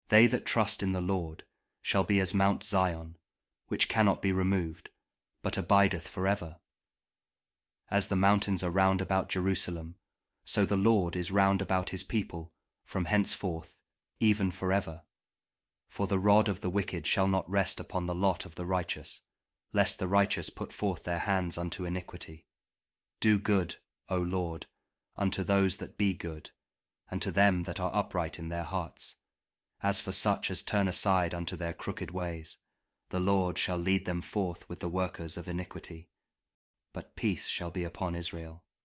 Psalm 125 | King James Audio Bible
psalm-125-kjv-king-james-audio-bible-word-aloud.mp3